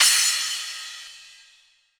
• Crash One Shot F Key 20.wav
Royality free crash sample tuned to the F note.
crash-one-shot-f-key-20-5LX.wav